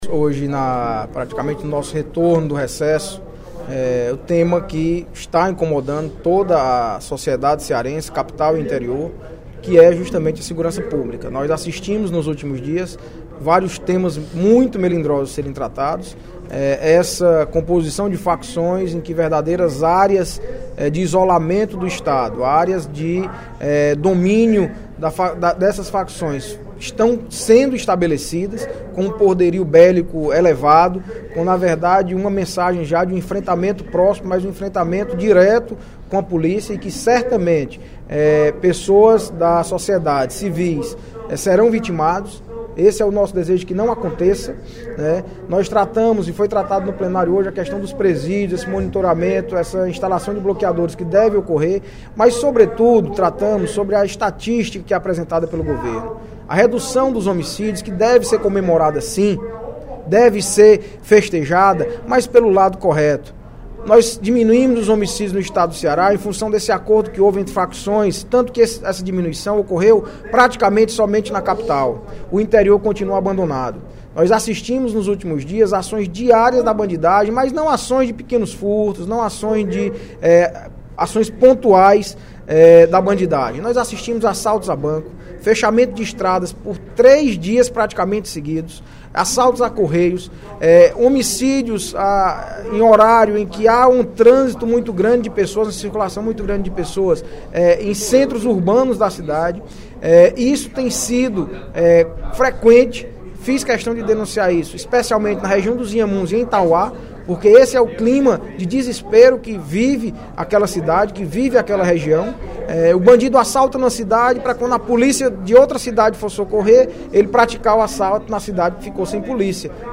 O deputado Audic Mota (PMDB) criticou,  no primeiro expediente da sessão plenária da Assembleia Legislativa desta sexta-feira (05/02), a falta de ações na área da segurança pública no Estado.